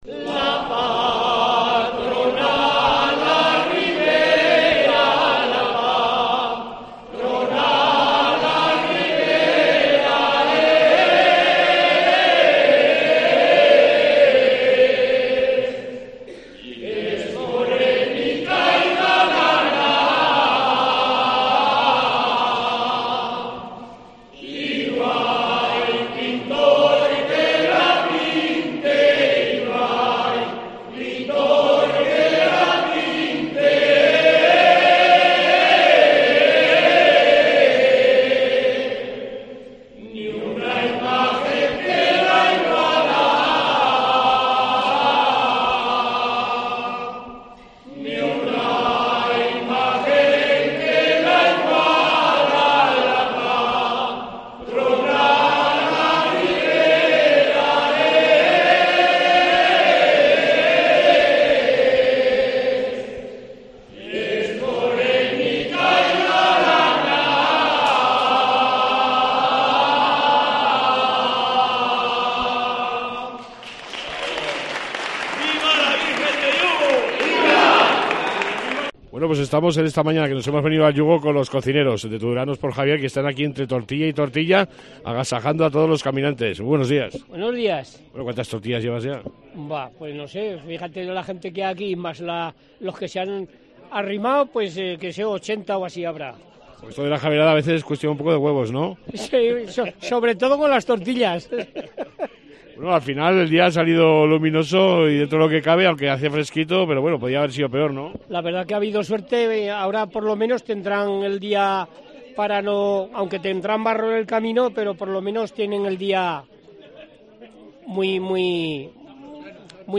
AUDIO: Esta mañana hemos estado en el Yugo y hemos hablado con muchos peregrinos de diferenres localidades